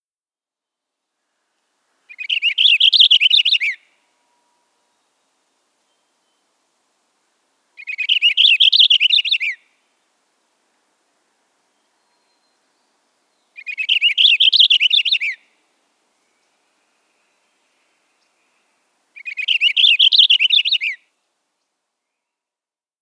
Purple Finch
How they sound: The song of the Purple Finch sounds like a slurred warbling and their typical call note is a short, low tek .